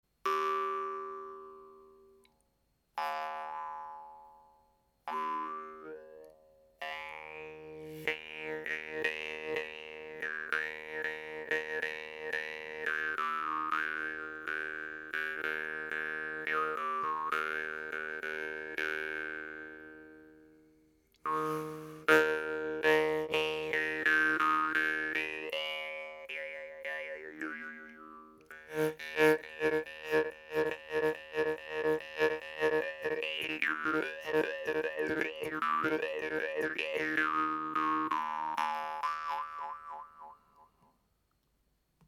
Facile à prendre en main, un look rustique, un son très agréable pouvant même être puissant, voici une guimbarde plutôt sympatique pour un débutant ou un joueur avancé!